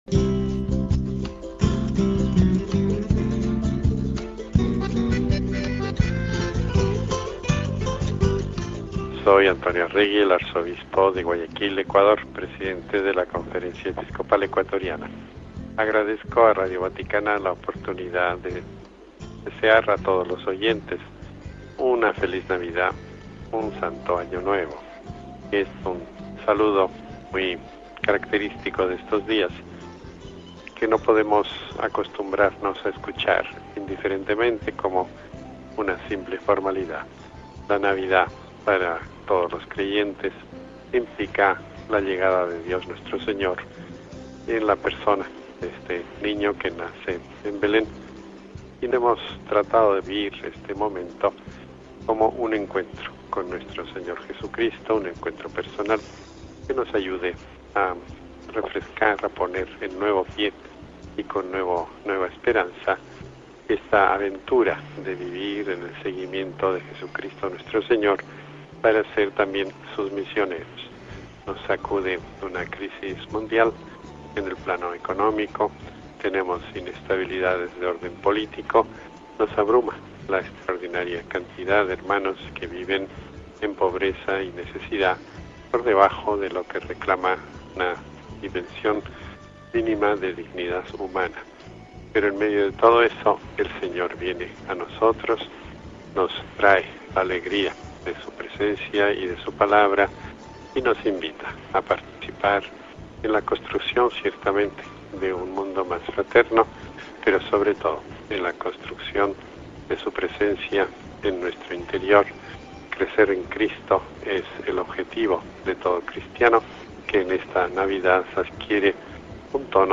Cuando estamos celebrando este período navideño los obispos, los pastores de América Latina desde sus países se dirigen a todos nuestros oyentes con un mensaje de Navidad.